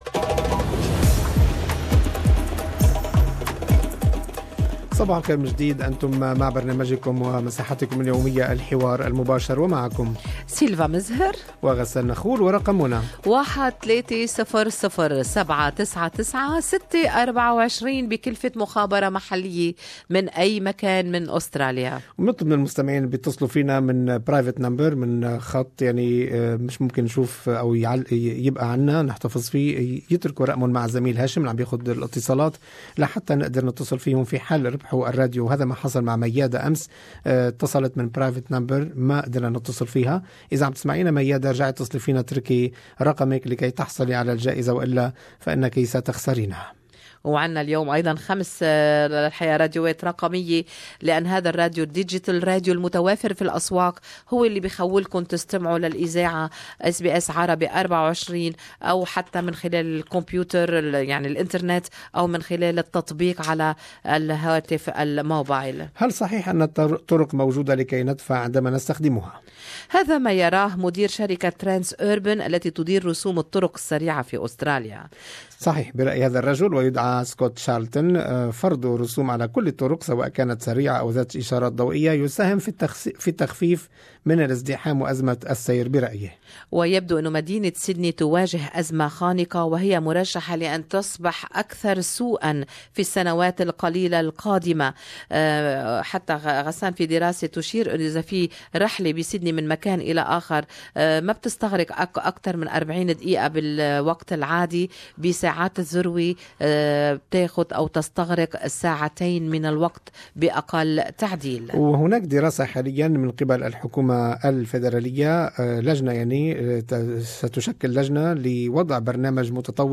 Talkback listeners opinions